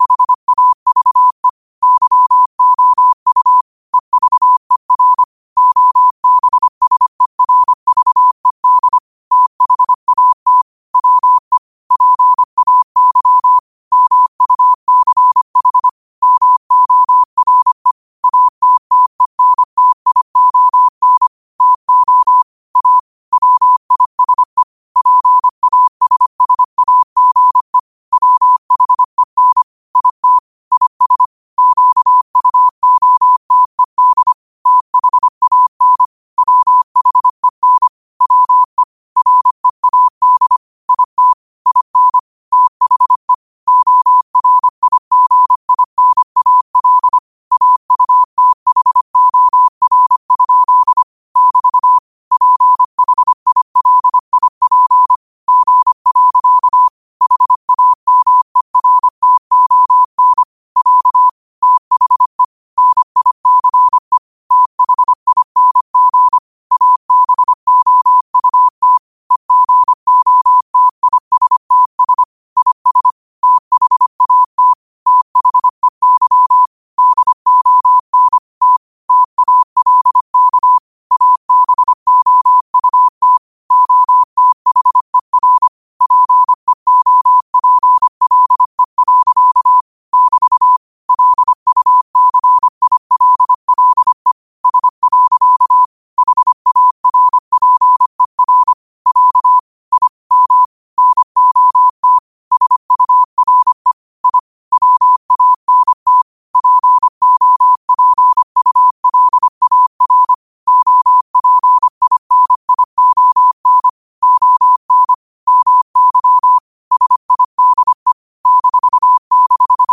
25 WPM morse code quotes for Wed, 13 Aug 2025 by QOTD at 25 WPM
Quotes for Wed, 13 Aug 2025 in Morse Code at 25 words per minute.